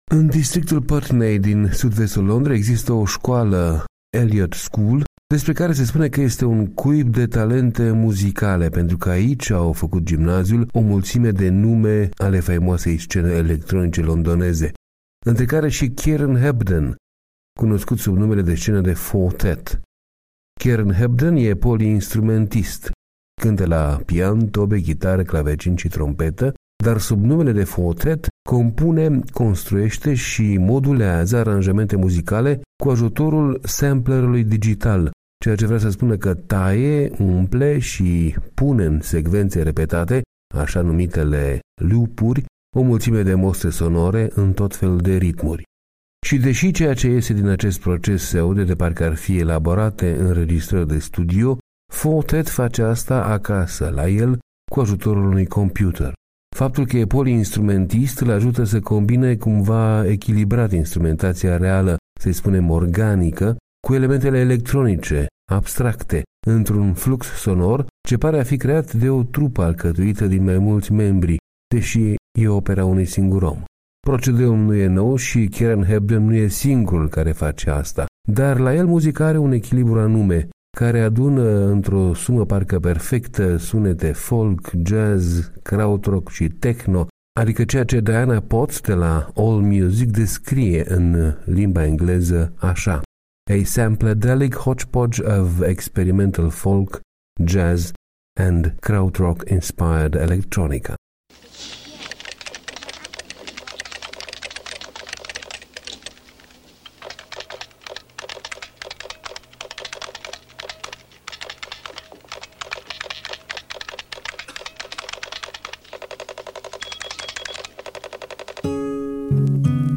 A sampladelic hodgepodge of experimental folk, jazz, and Krautrock-inspired electronica.